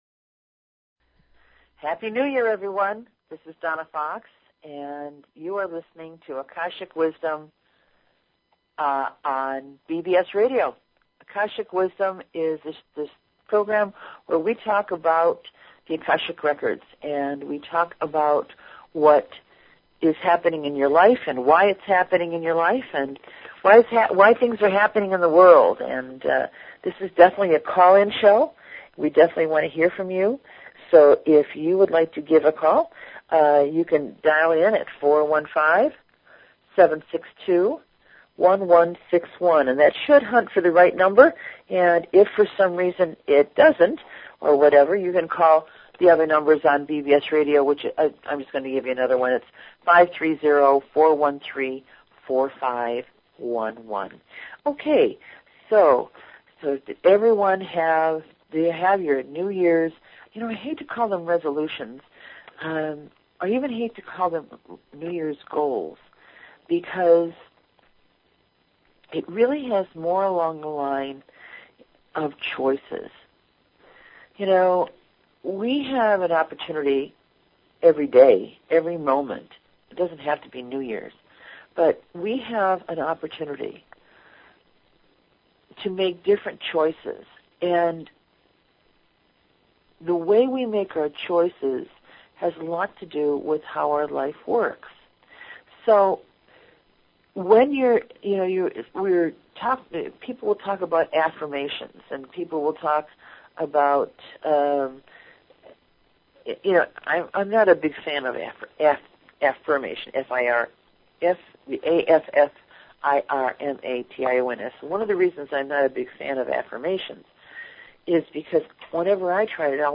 Talk Show Episode, Audio Podcast, Akashic_Wisdom and Courtesy of BBS Radio on , show guests , about , categorized as